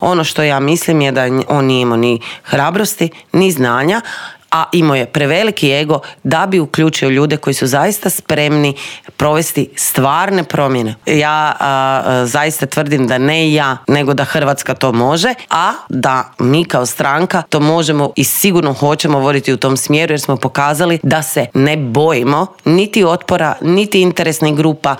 ZAGREB - U Intervjuu tjedna Media servisa gostovala je saborska zastupnica i premijerska kandidatkinja stranke Možemo Sandra Benčić, koja je prokomentirala nove izmjene koje je Vlada najavila uvrstiti u konačni prijedlog tzv. Lex AP-a, osvrnula se na reakciju vladajućih na jučerašnji prosvjed HND-a zbog kaznenog djela o curenju informacija, ali i na kandidata za glavnog državnog odvjetnika Ivana Turudića.